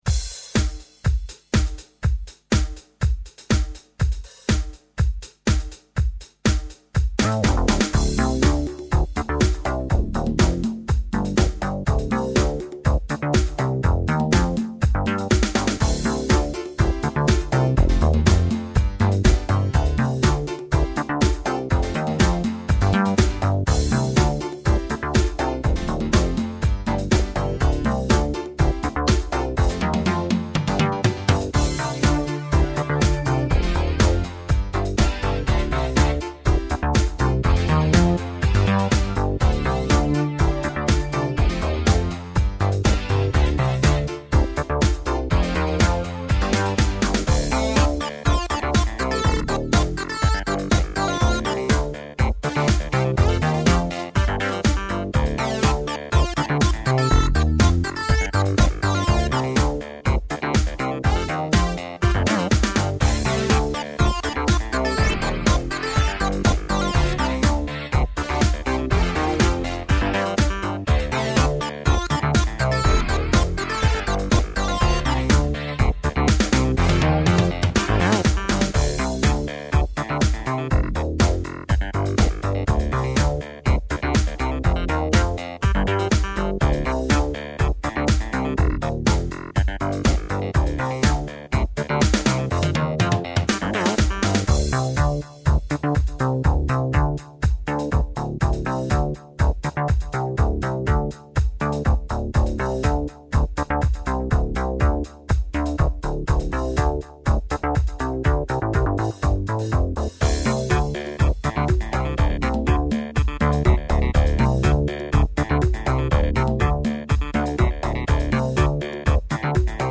MS2000 på bas och JP-8000 på synklead (melodin):